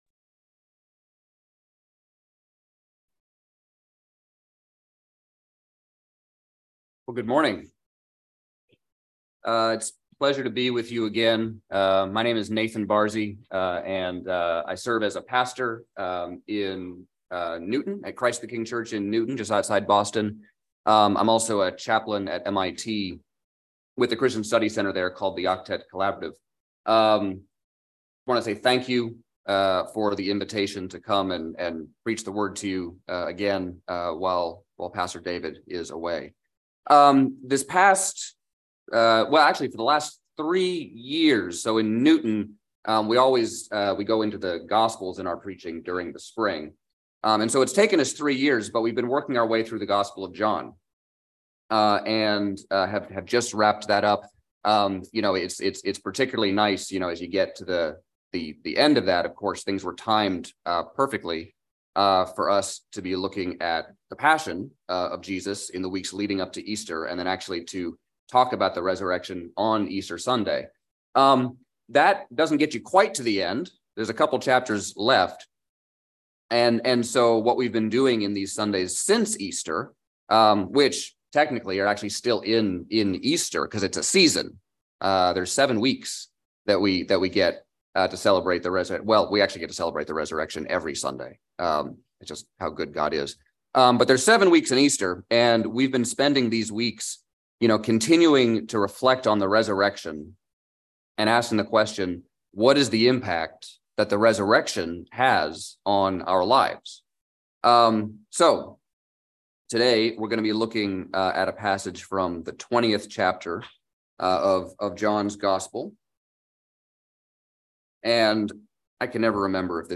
by Trinity Presbyterian Church | May 2, 2023 | Sermon